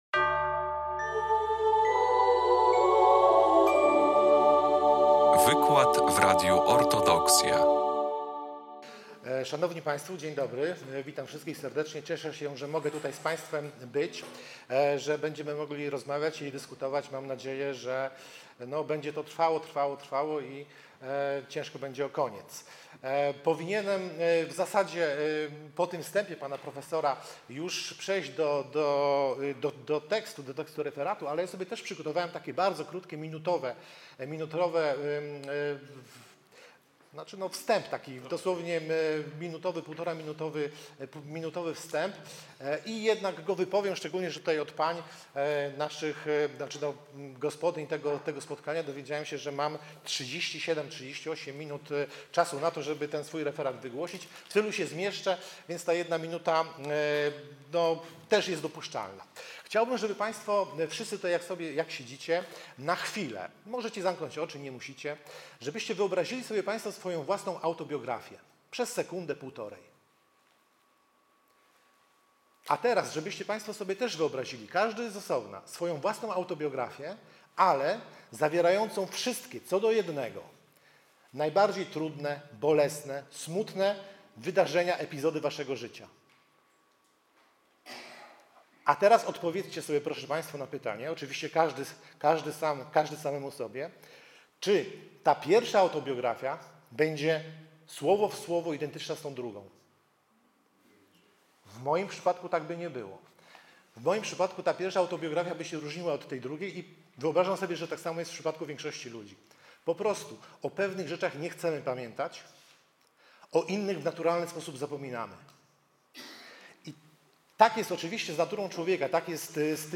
19 lutego 2026 roku w Centrum Kultury Prawosławnej w Białymstoku odbyło się spotkanie organizowane przez Stowarzyszenie Ruś.